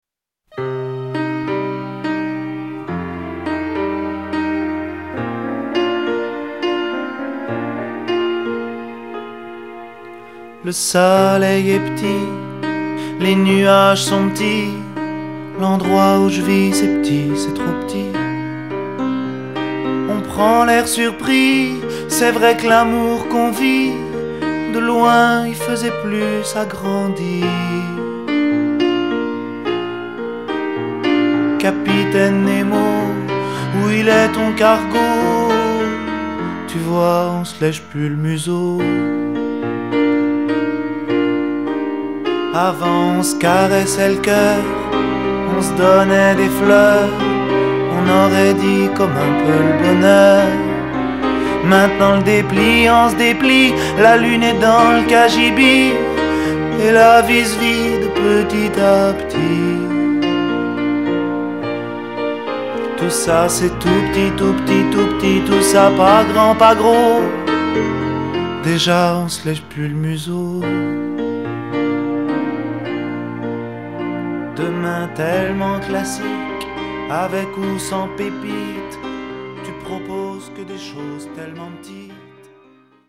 tonalité Mib